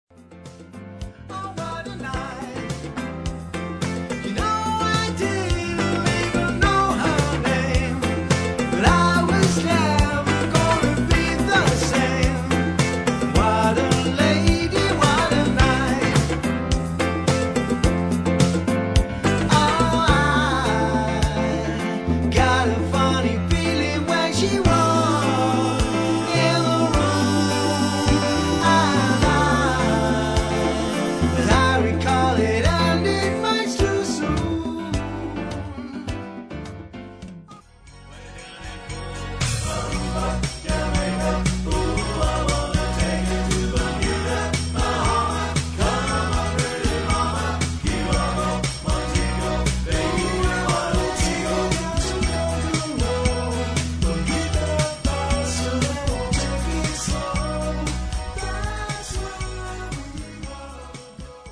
Classic Rock & Rock n Roll - Beach Boys Party Band